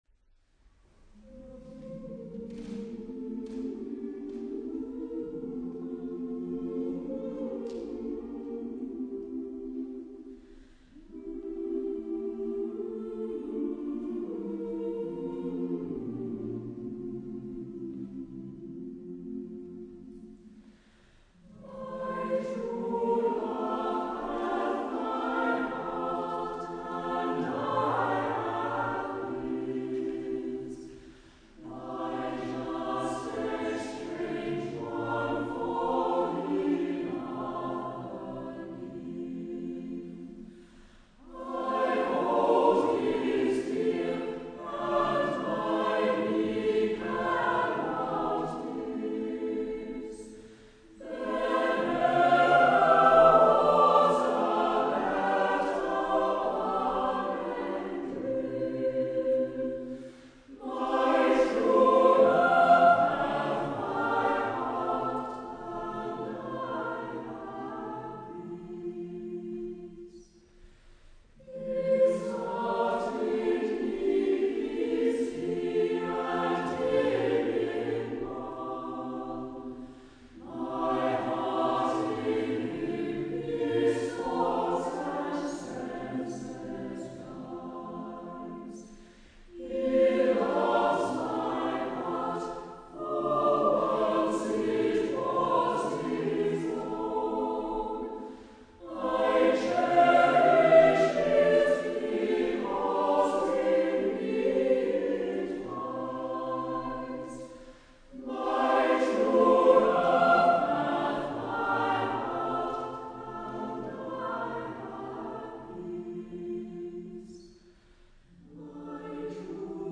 Part 2: Romantic Partsongs
The term partsong just means a song for more than one voice, normally for a cappella choir, and applies predominantly to Romantic English and German choral songs.
Soprano Alto Tenor Bass